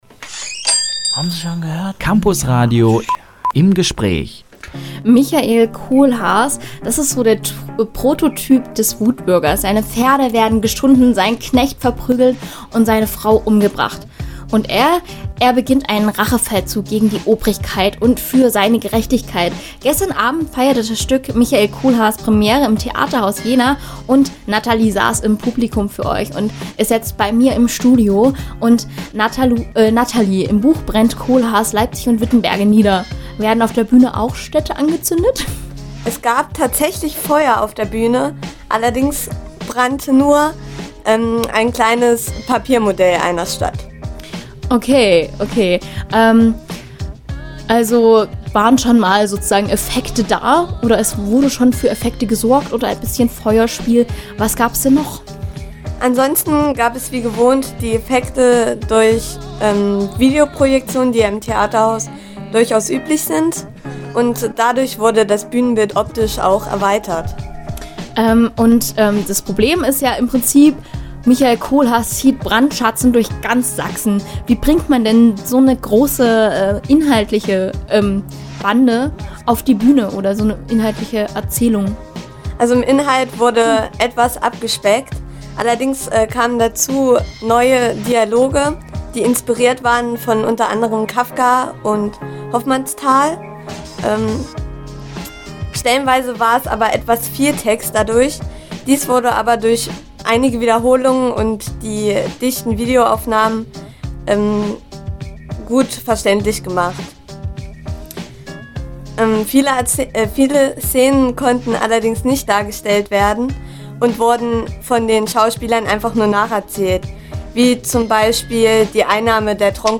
Theaterrezension: Michael Kohlhaas – Campusradio Jena